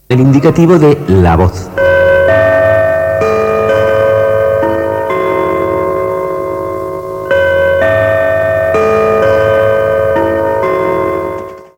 Indicatiu de la cadena